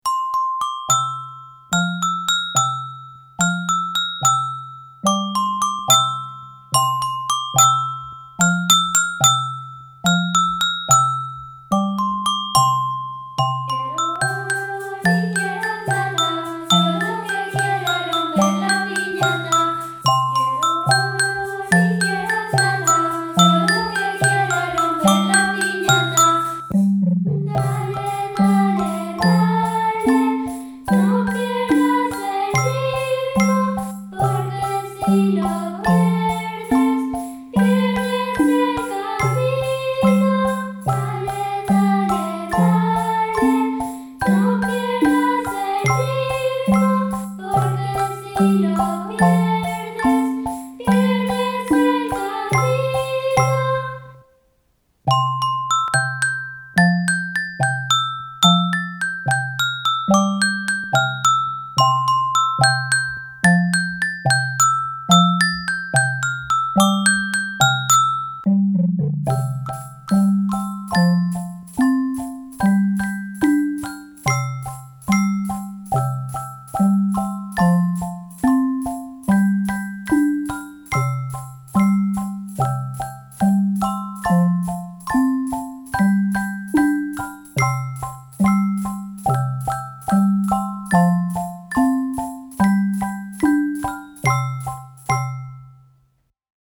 Voz e acompañamento